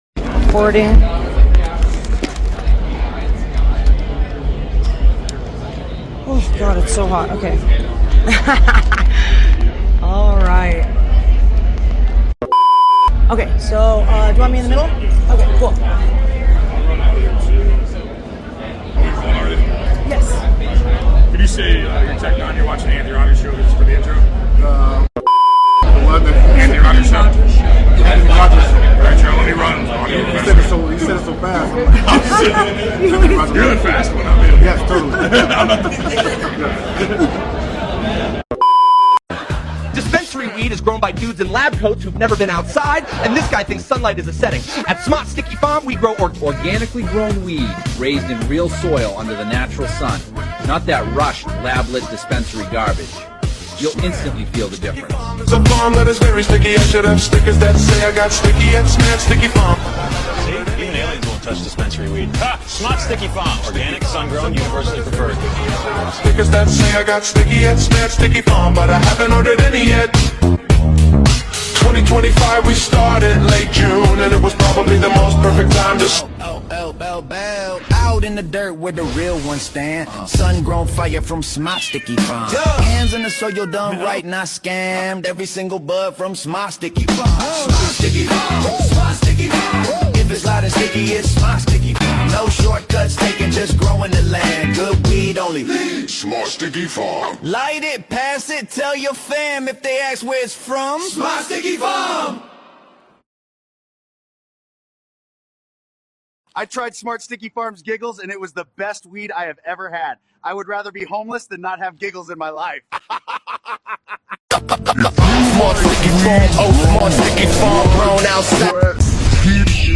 sit down with Tech N9ne backstage in Louisville, KY at Louder Than Life 2025 for an exclusive interview covering music, longevity, independence, and the energy of one of the biggest rock and hip-hop festivals in the country.